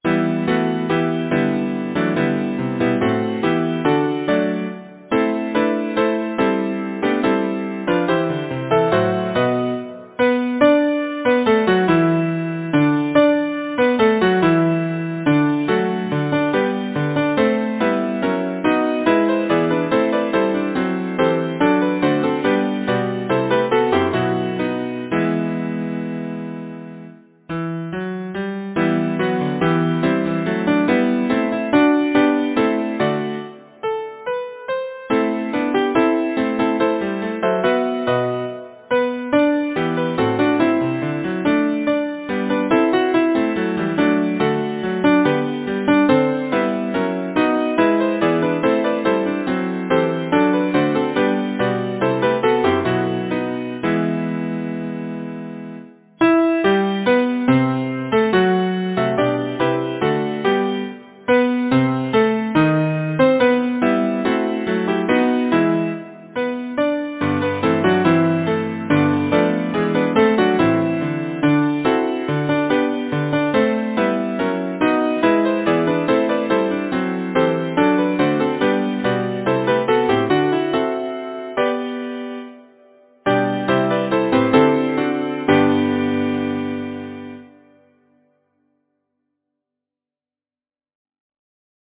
Title: Who would true valour see Composer: Geoffrey Shaw Lyricist: John Bunyan Number of voices: 4vv Voicing: SATB, divisi Genre: Secular, Partsong
Language: English Instruments: A cappella